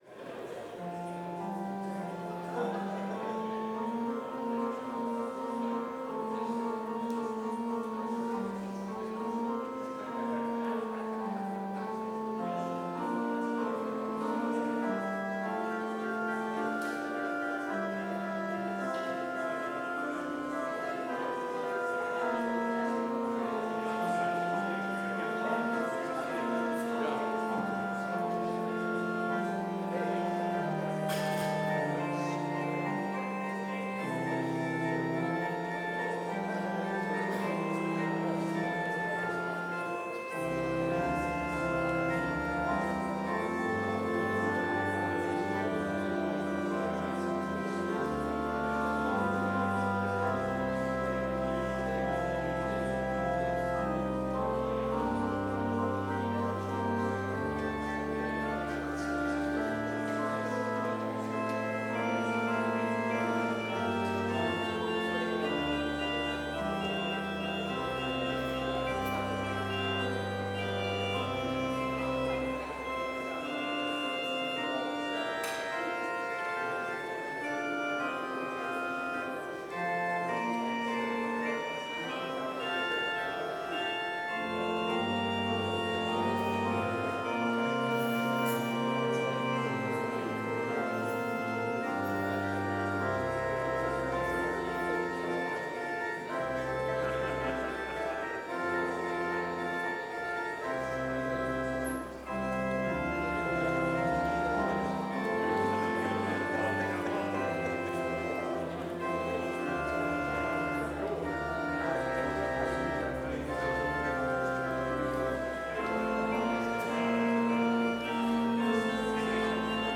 Complete service audio for Chapel - Tuesday, November 25, 2025
Prelude Hymn 33 - We Now Implore God the Holy Ghost
Devotion Prayer Hymn 249 - Fight the Good Fight With All Your Might View Blessing Postlude